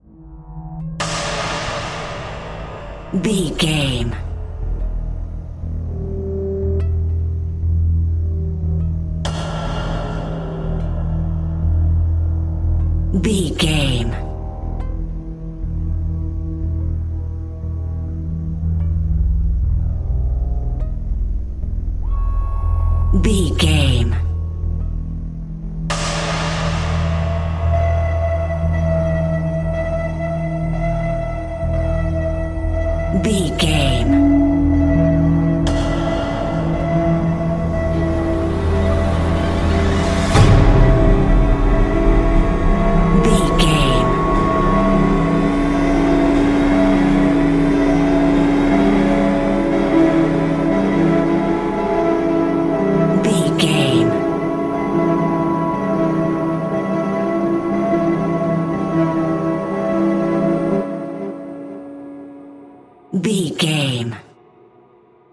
In-crescendo
Atonal
Slow
scary
ominous
eerie
synthesiser
strings
Horror Synth Ambience